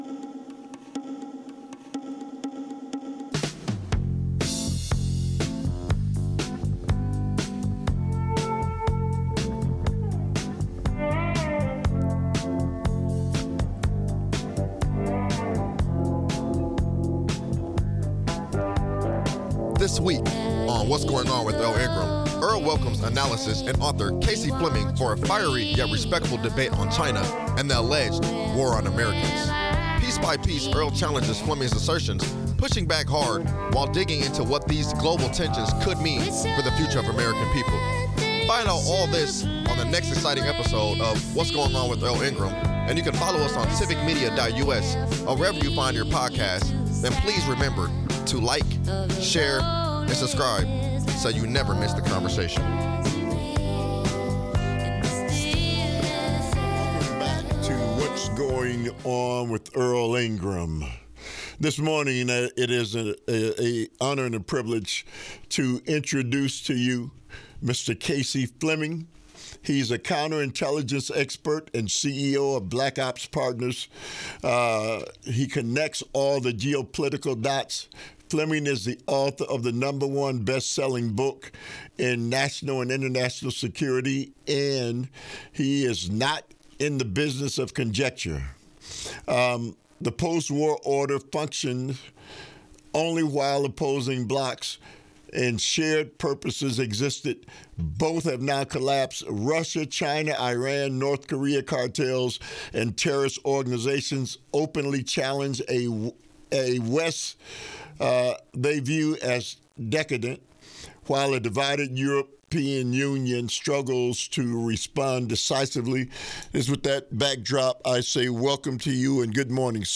In a heated yet respectful debate